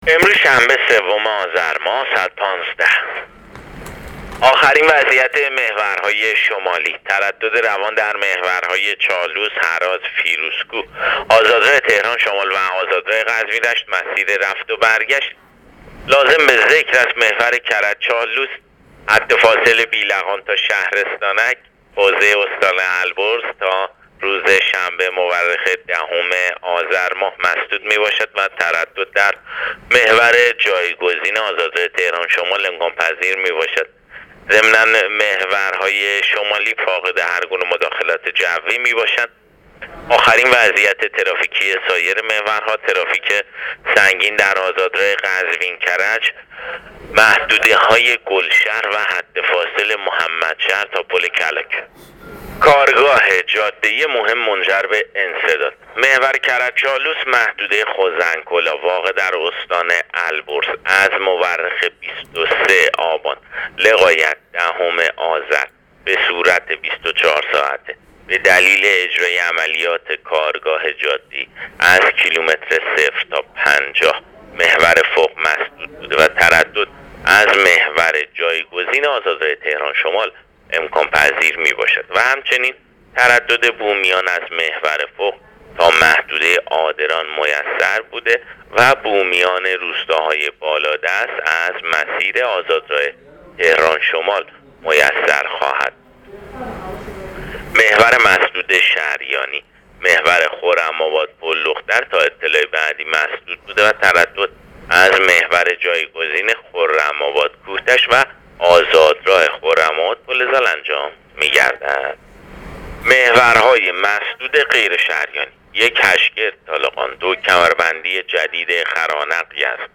گزارش رادیو اینترنتی از آخرین وضعیت ترافیکی جاده‌ها تا ساعت ۱۵ سوم آذر؛